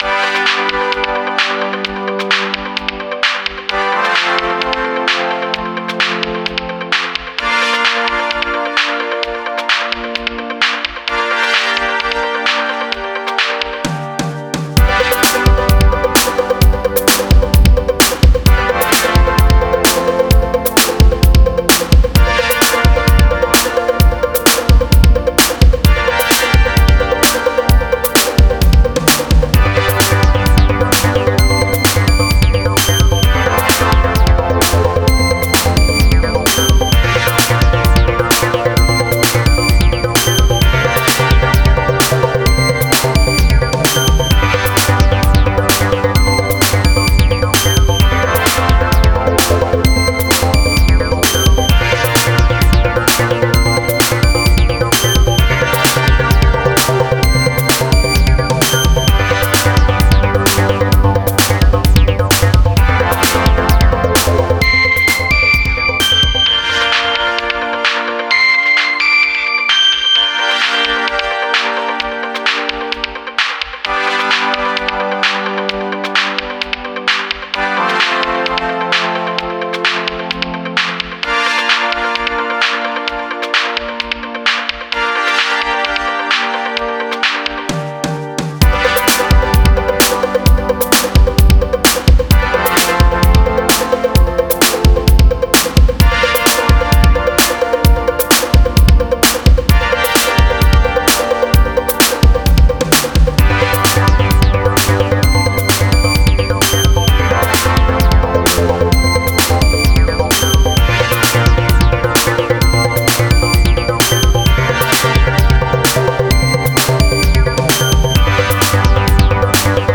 80s,Disco,Life.wav